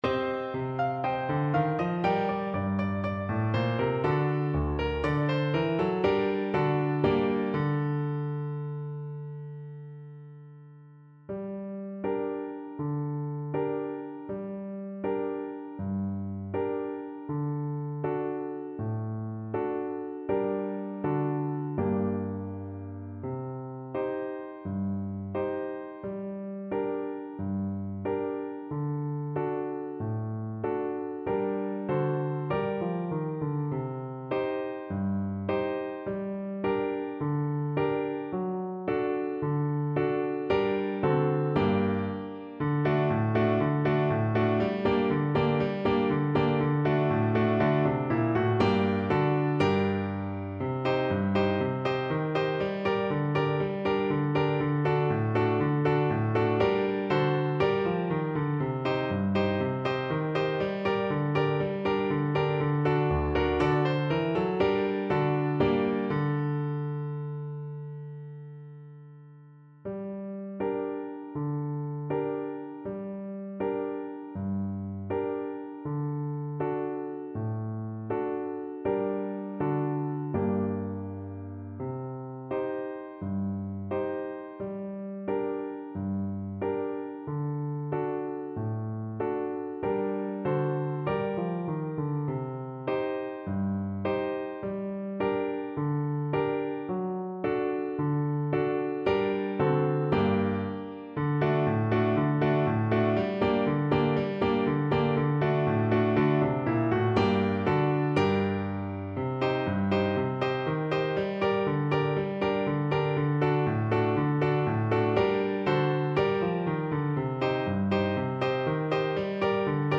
Allegro =c.120 (View more music marked Allegro)